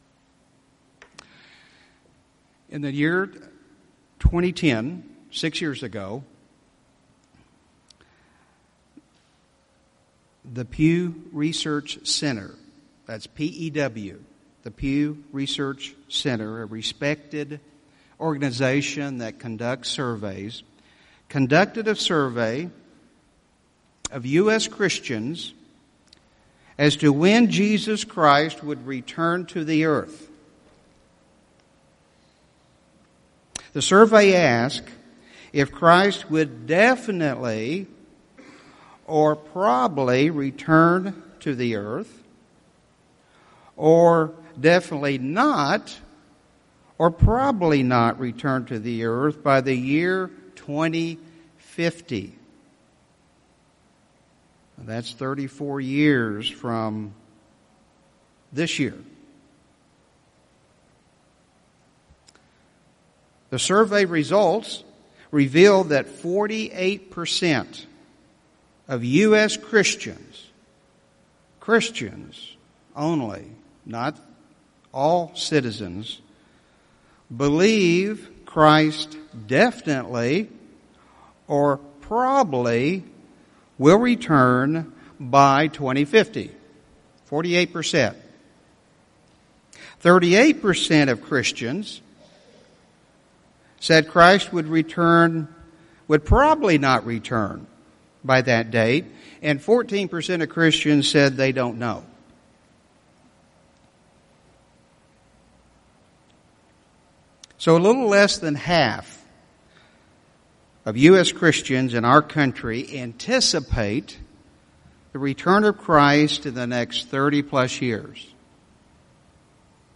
Sermons
Given in Tulsa, OK